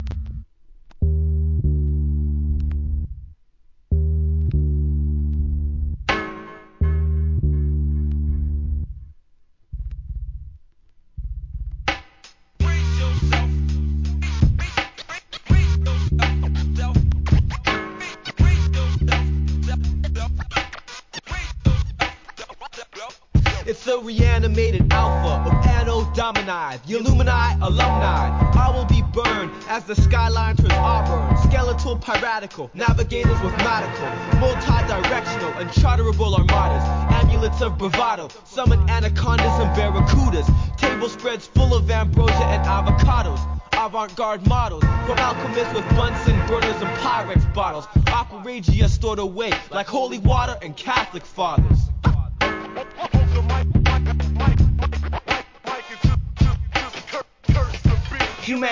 HIP HOP/R&B
随所のスクラッチも聴き所!